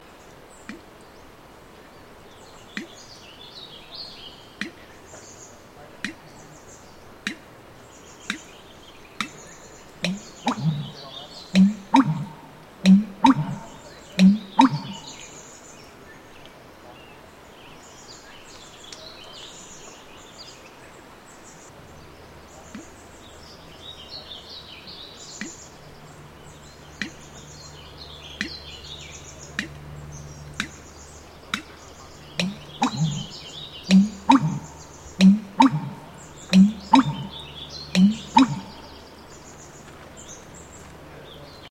My day began waking at 3:15 am and being on location near an area wetland to listen in darkness for a variety of species – including bitterns, rails, owls, and an assortment of song birds – that call out while still dark to establish their territory or to call in a mate.
The American bittern, for example, sounds like someone is submerging an empty glass up-side-down into water and tipping it to let air out and water in or something dropped into water making a kerplunk sound.
All recordings were made available via xeno-canto, a website dedicated to sharing bird sounds from all over the world.